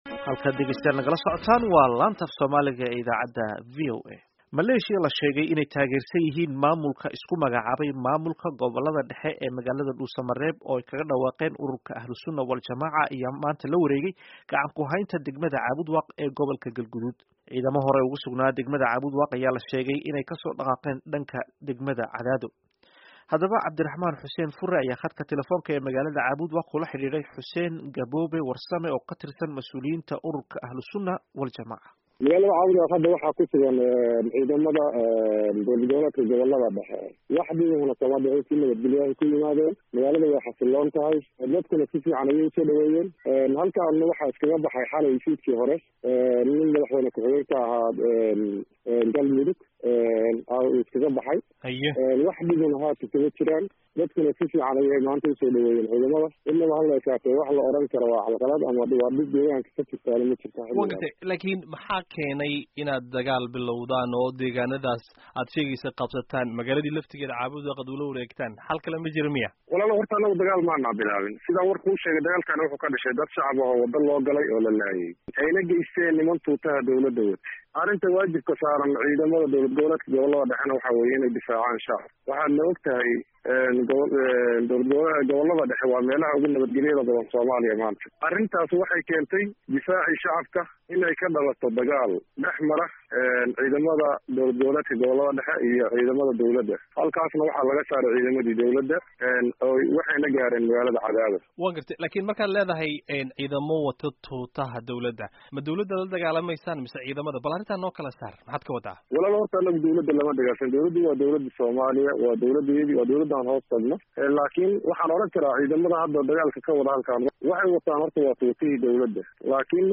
Wareysiga qabsashada Caabud-Waaq